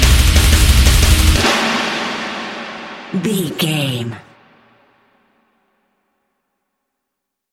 Aeolian/Minor
drums
electric guitar
bass guitar
hard rock
aggressive
energetic
intense
nu metal
alternative metal